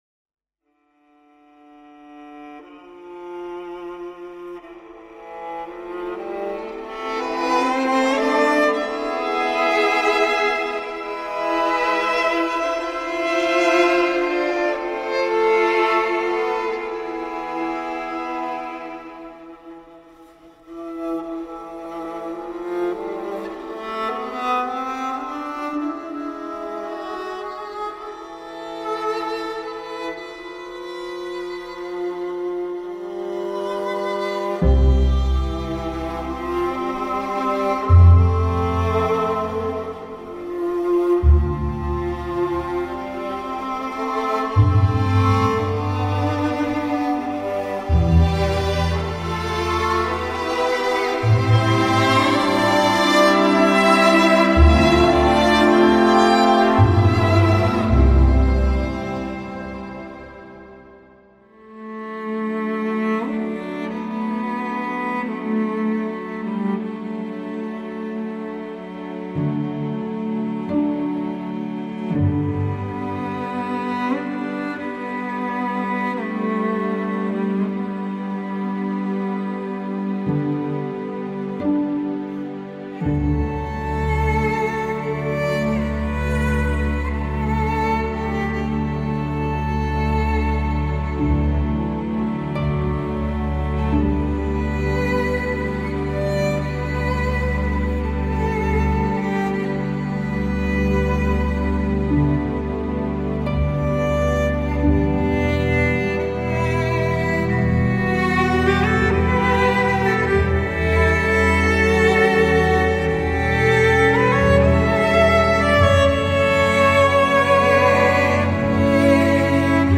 Malgré un effectif orchestral plutôt réduit
procédé d’écriture hérités du baroque